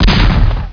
banglrg.wav